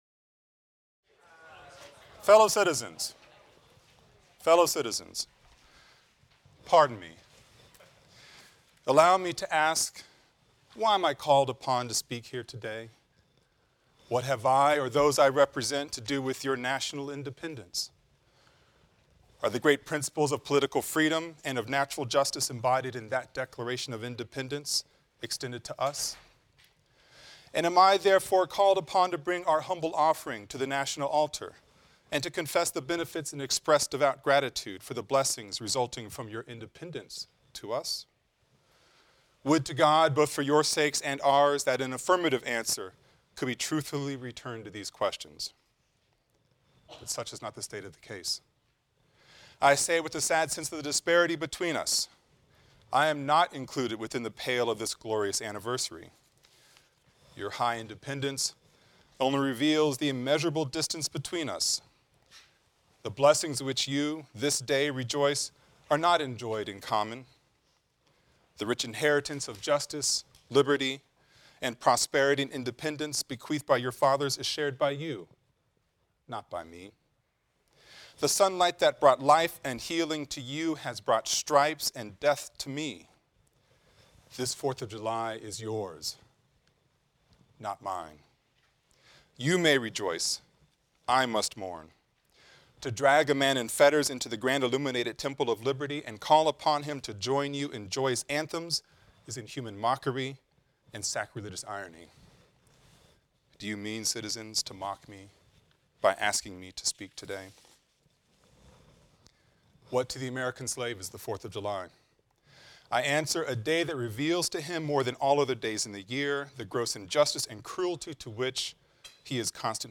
AFAM 162 - Lecture 1 - Dawn of Freedom | Open Yale Courses